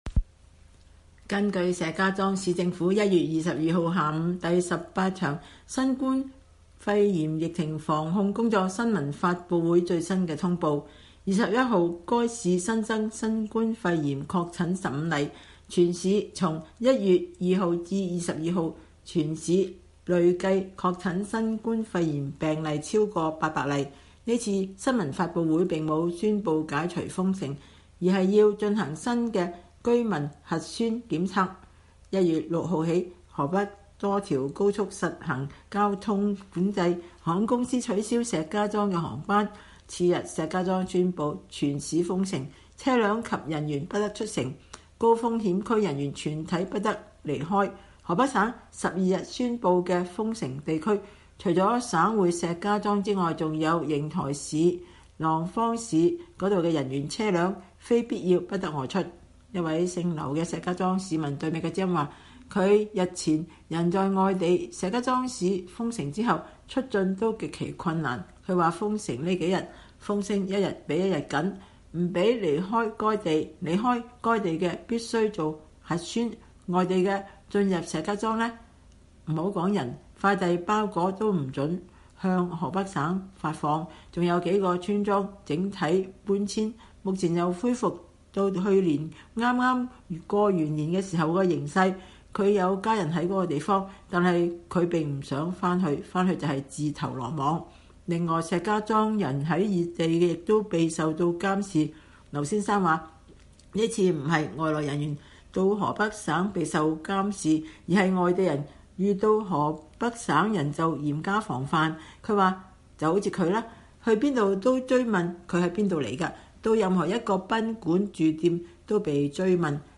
隨著石家莊等地疫情日趨嚴重，河北省官員在實施封城的同時，誓言要做好保衛北京安全的“護城河”。此外，面對即將開始的春運潮，各地當局還採取了“就地過年”的措施。但是，受到封城影響的市民在接受美國之音的採訪時對疫情通報不透明、物價突漲以及無法返鄉與家人團聚表示了不滿。